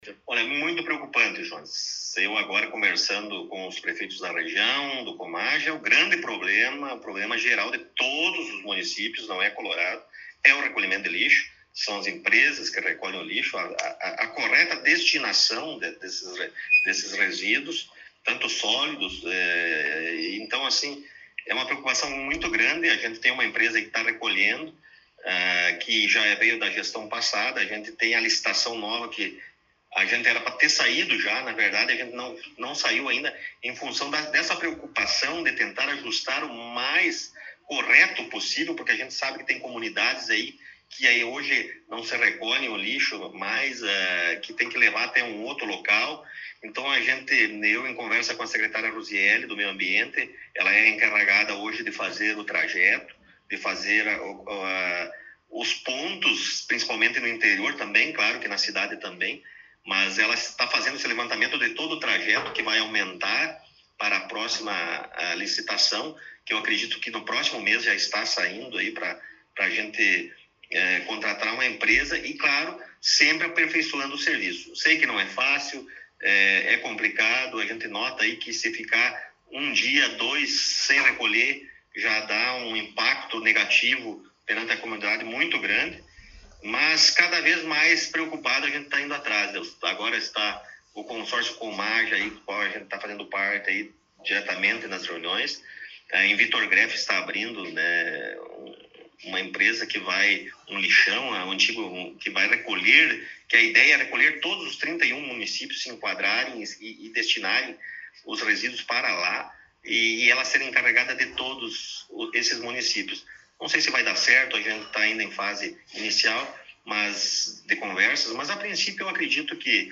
Prefeito Municipal Rodrigo Sartori concedeu entrevista